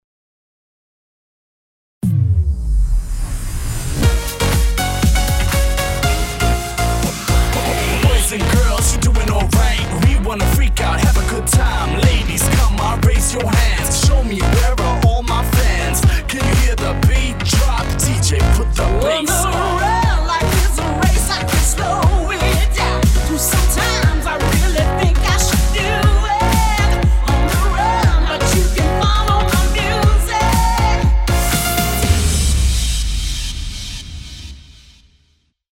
Level 2 - My Life Is a Race - Slower Tempo.mp3.mp3